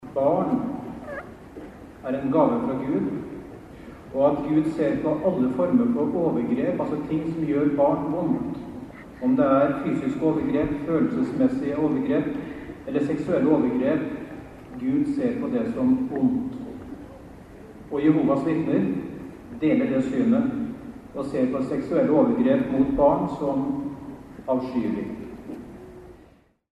Under landsstevnet på Lillestrøm var det såkalte symposier med undervisning av de mange tusen deltakerne. Ett av symposiene handlet om å beskytte barn mot seksuelle overgrep.